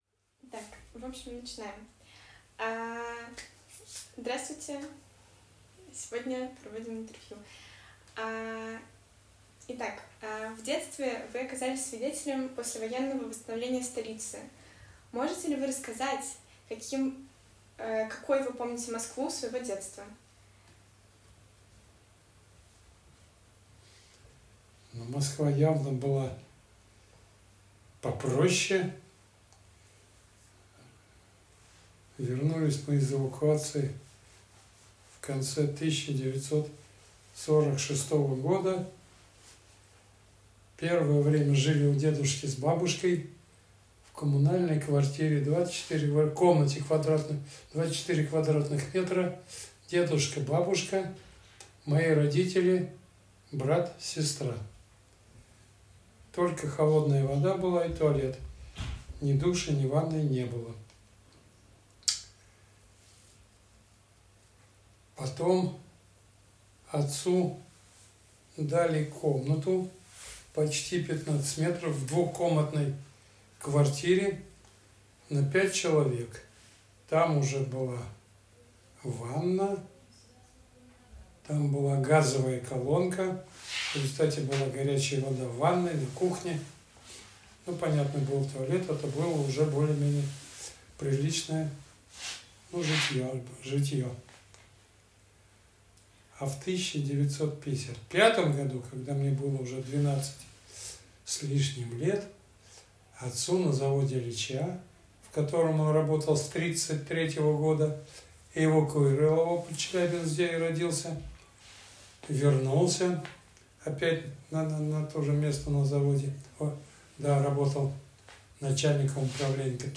Тип источника интервью
Интервью_советскии_зритель.mp3